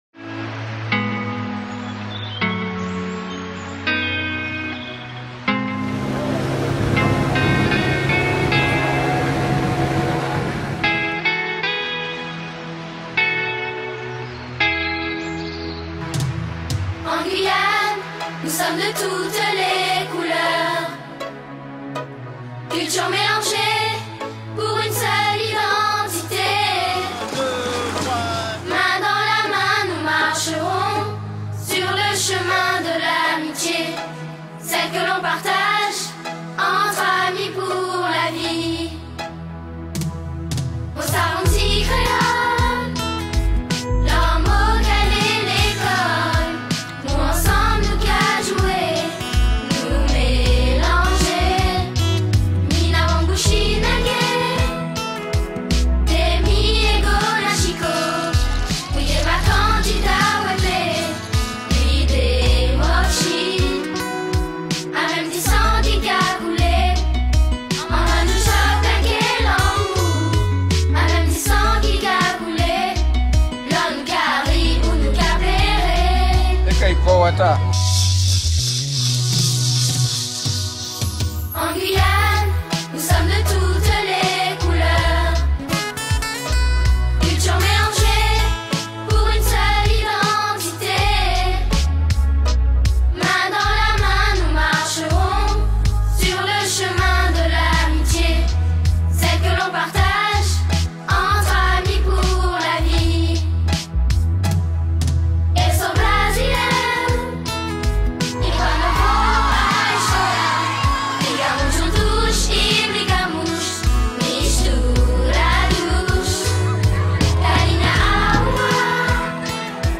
🎶 Hymnes nationaux du Pays de l’or